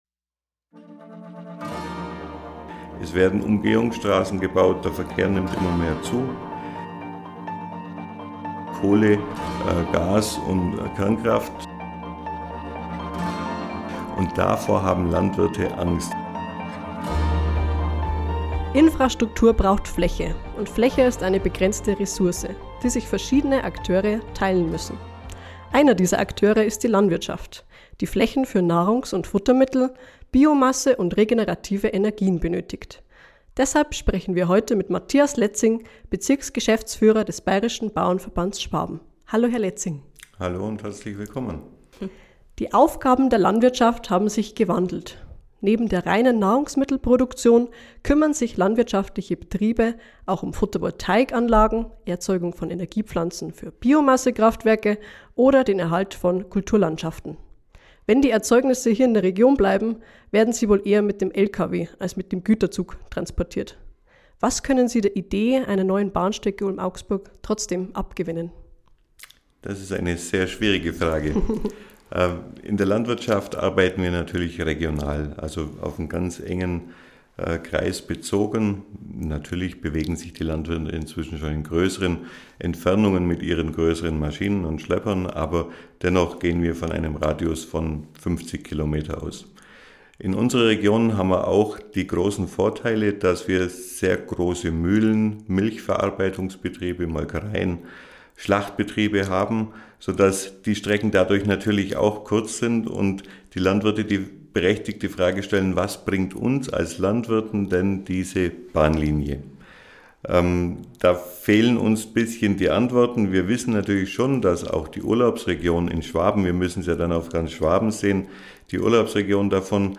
Eine Bahnstrecke Ulm-Augsburg – egal, wo sie letztlich verläuft – nimmt Fläche in Anspruch, die auch die Landwirte brauchen. Ein Gespräch über durchschnittene Grundwasserströme, lange Sommer und darüber, wo Bahn und Landwirtschaft am gleichen Strang ziehen.